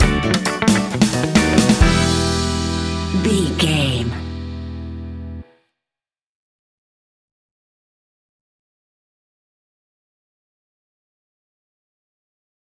A groovy piece of upbeat Ska Reggae!
Aeolian/Minor
Fast
reggae
dub
laid back
off beat
drums
skank guitar
hammond organ
percussion
horns